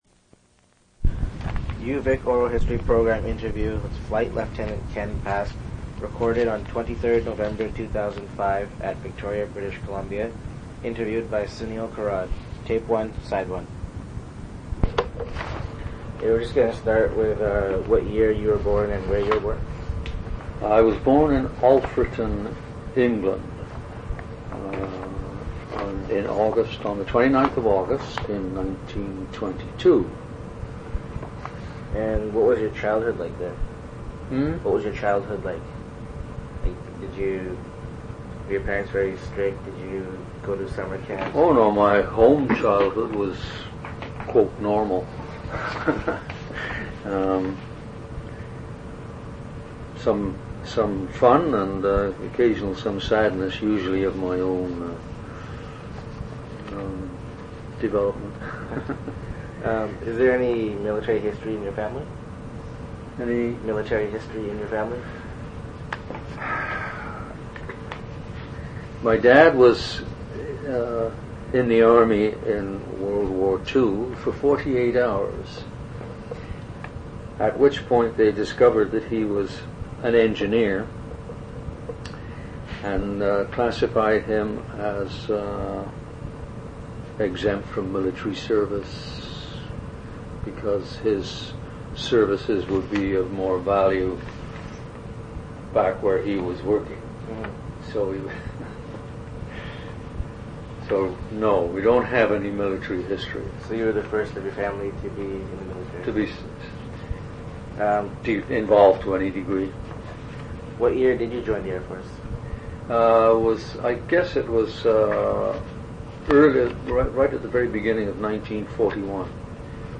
Personal narratives--Canadian Military history